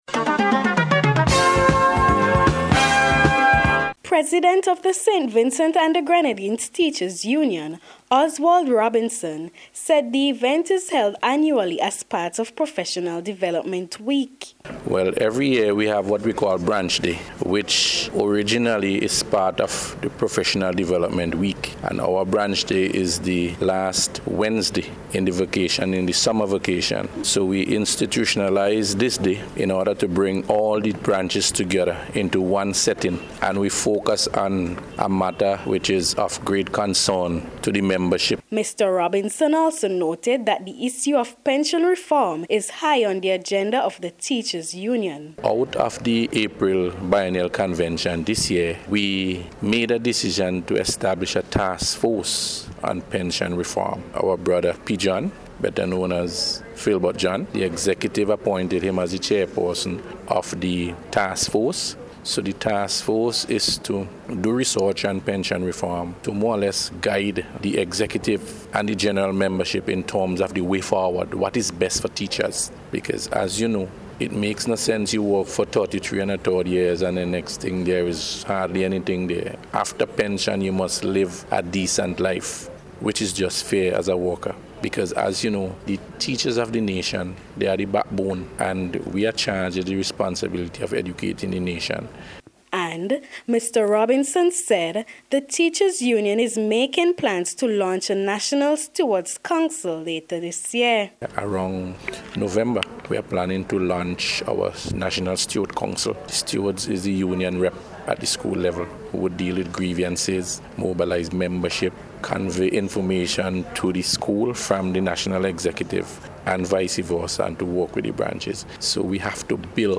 SVGTU-BRANCH-DAY-REPORT-.mp3